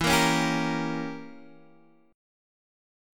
Gdim/E chord